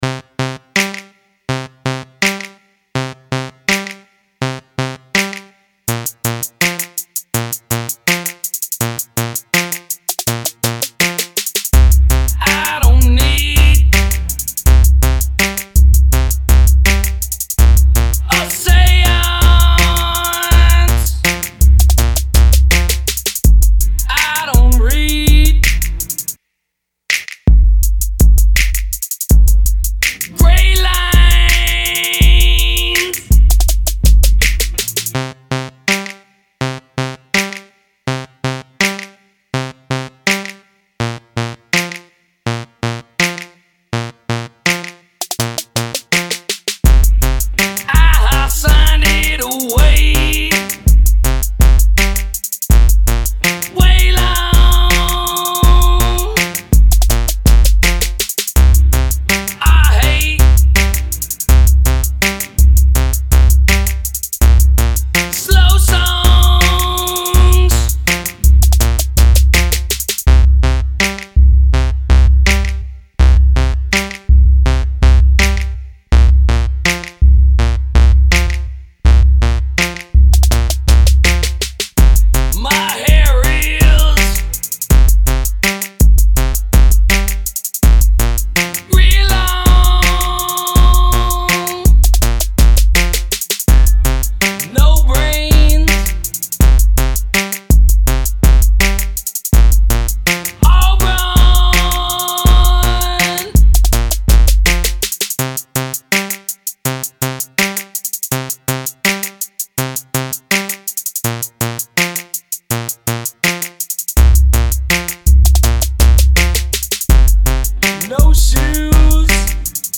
all mashups